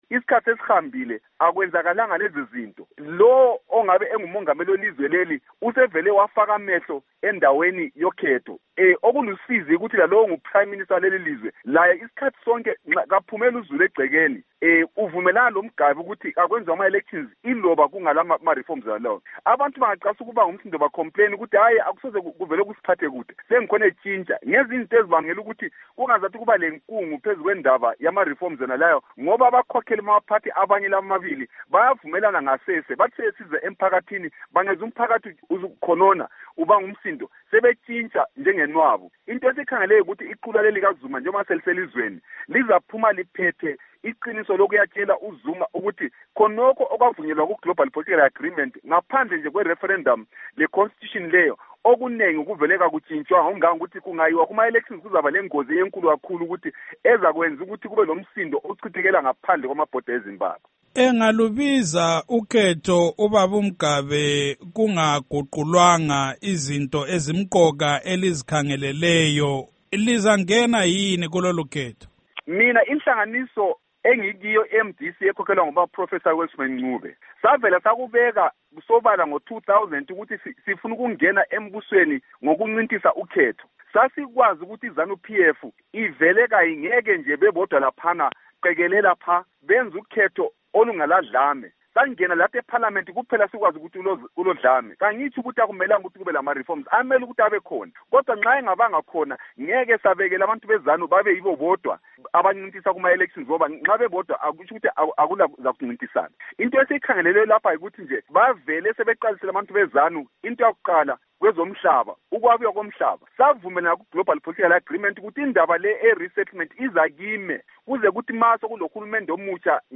Ingxoxo LoMnu Moses Mzila Ndlovu